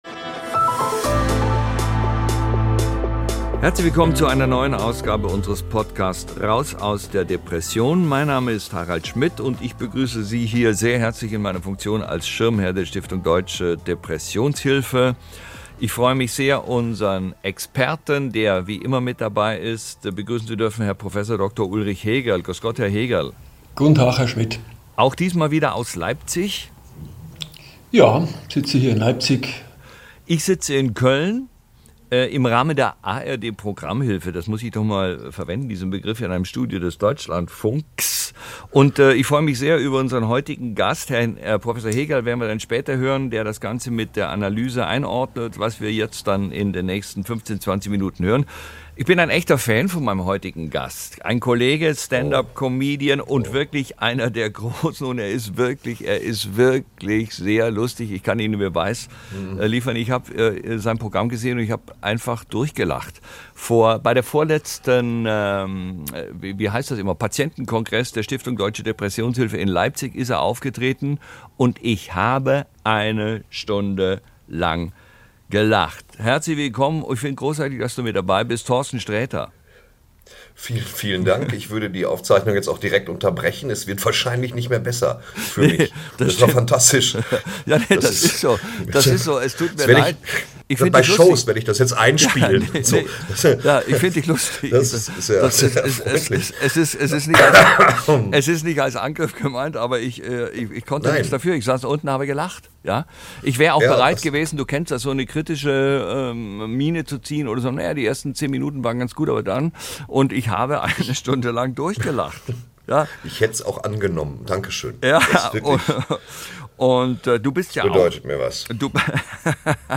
Wie das ist, mit Depression bei Männern, mit dem Humor und mit Medikamenten bei Depression – darüber spricht Torsten Sträter mit Harald Schmidt, der auch in der letzten Folge der dritten Staffel dieses Podcasts seinen Gast als Schirmherr der deutschen Depressionshilfe begrüßt.